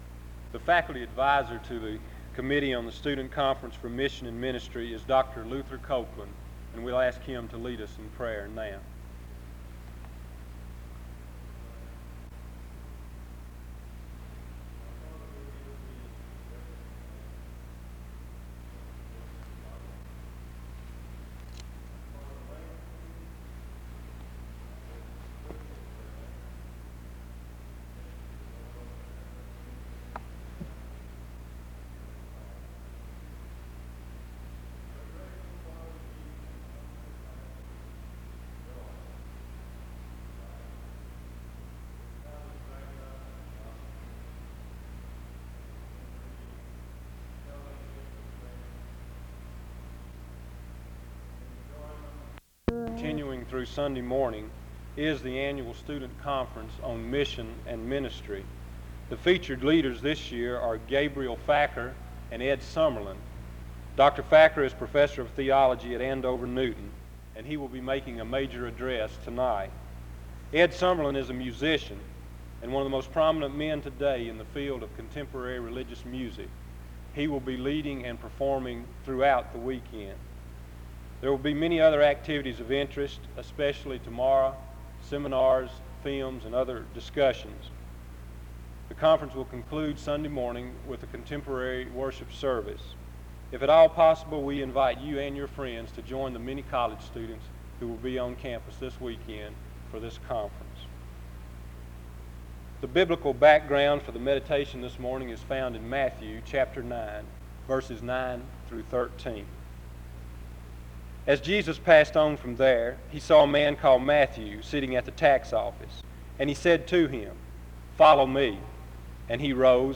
After which there is a time of announcements (0:53-1:41). Afterward the speaker reads Matthew 9:9-13 (1:42-2:35).
He continues by stating that the believer must also follow the voice of Jesus (5:26-8:17). In addition, he explains the cost of following Jesus is great (8:18-16:50). He concludes the service in prayer (16:51-17:17).